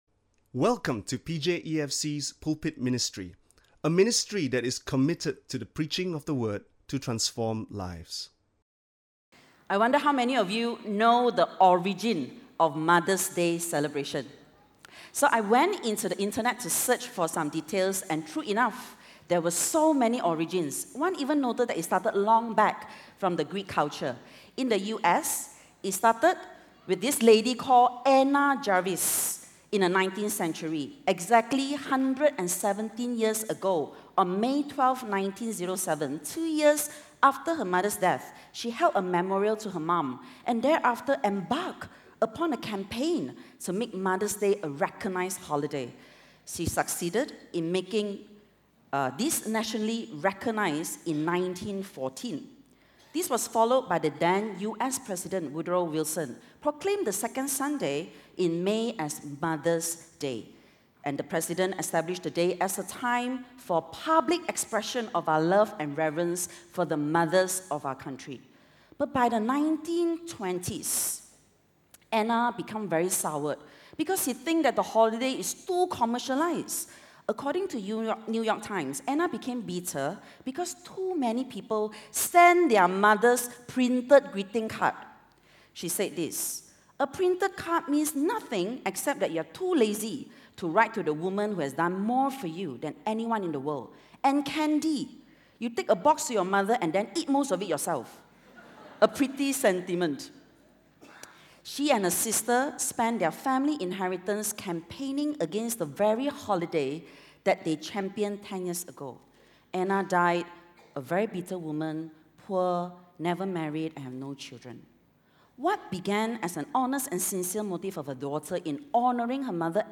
This sermon is in conjunction with Mother's Day.
Listen to Sermon Only